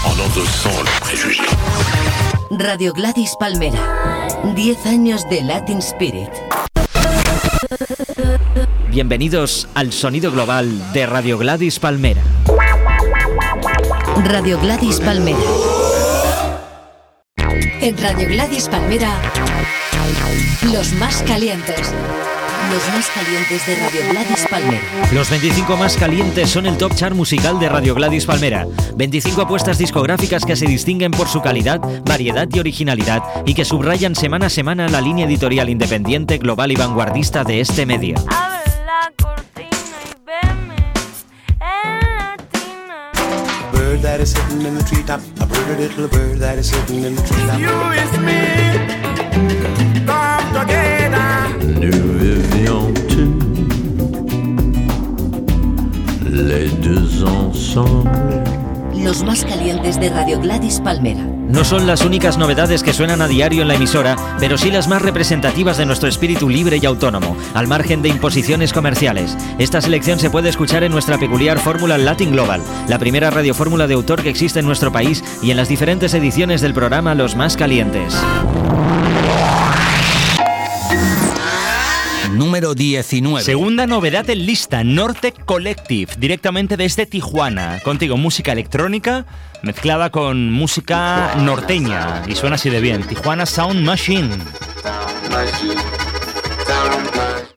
Indicatiu dels 10 anys de la ràdio, identificiació de l'espai i presentació del tema 19 de la llista
Musical